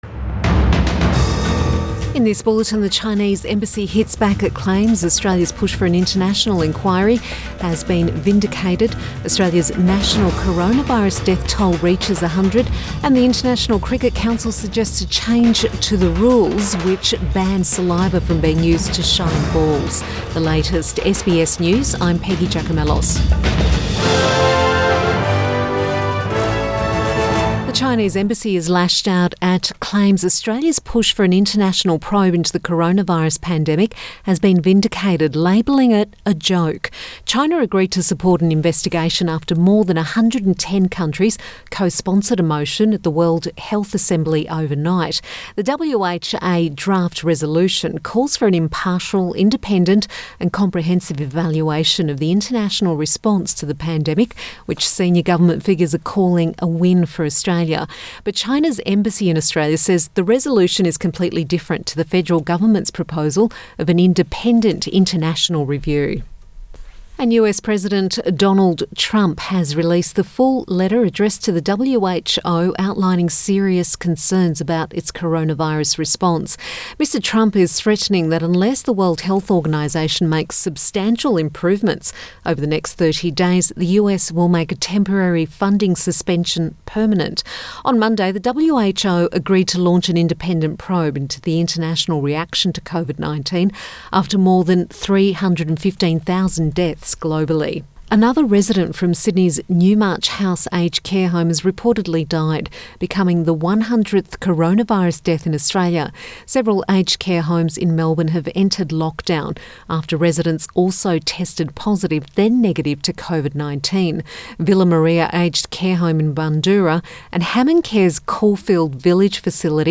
PM bulletin May 19 2020